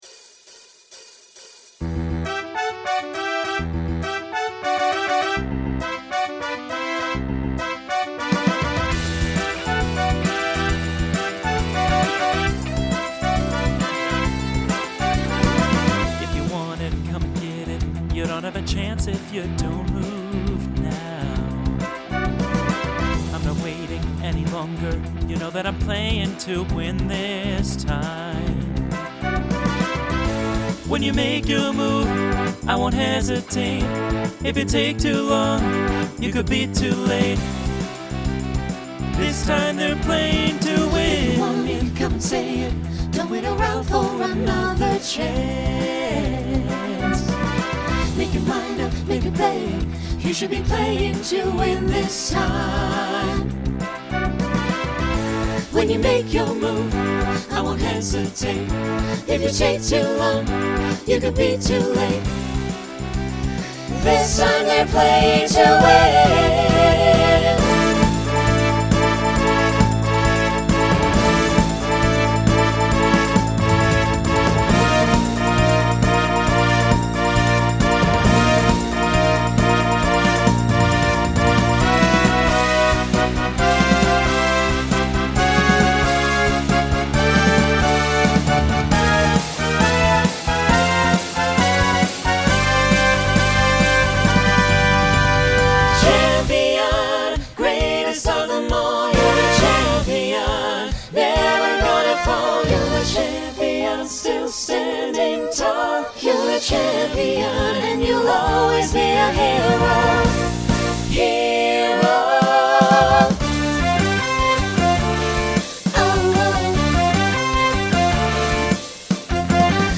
Genre Pop/Dance , Rock Instrumental combo
Story/Theme Voicing SATB